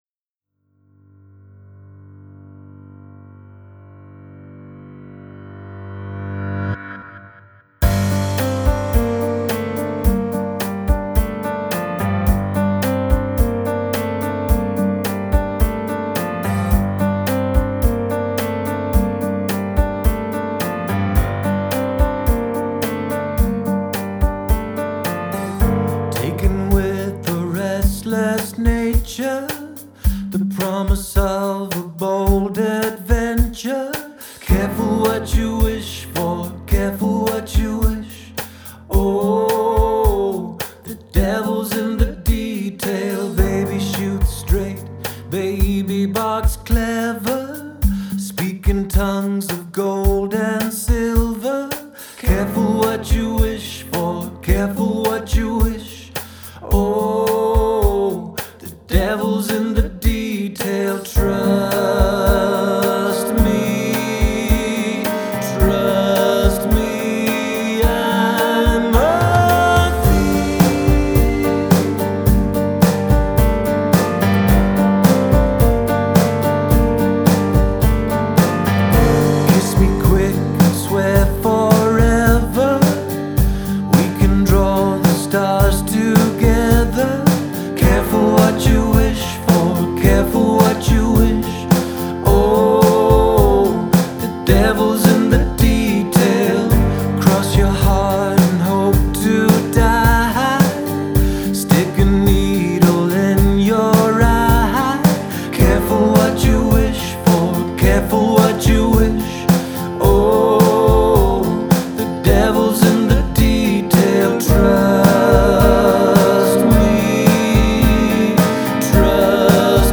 Genre: Indie, Folk